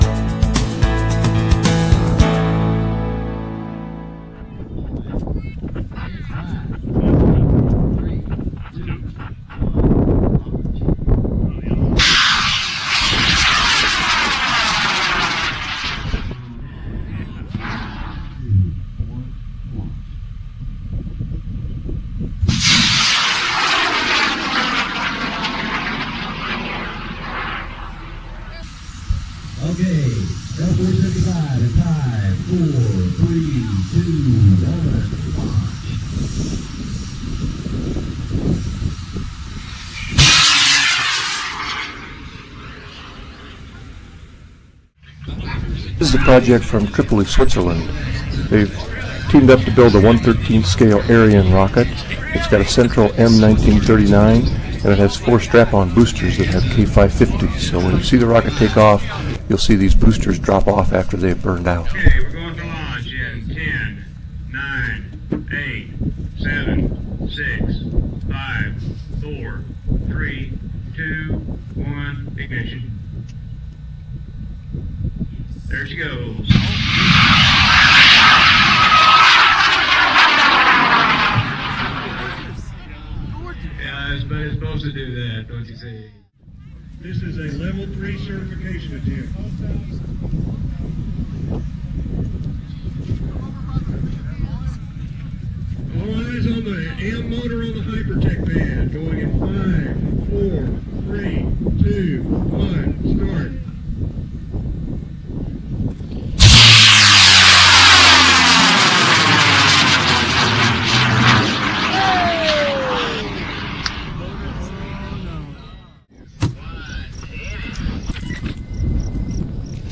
See some of the cool video taken at LDRS: